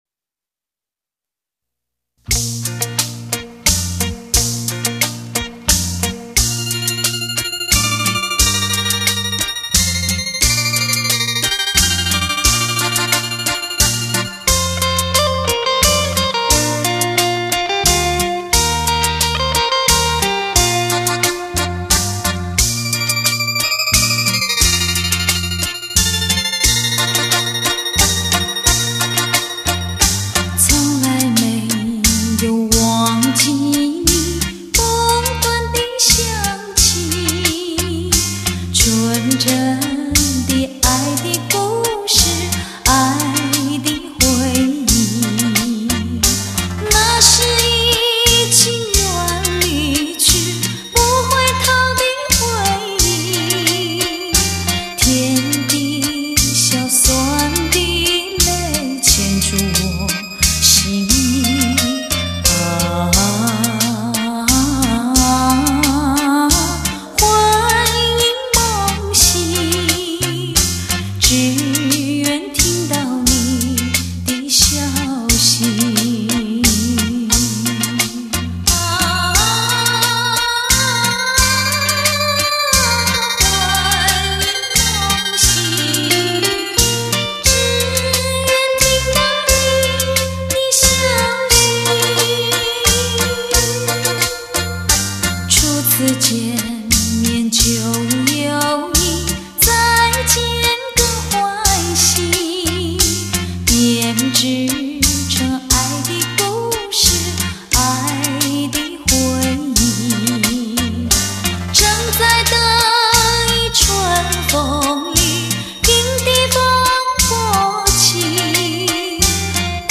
酒廊小调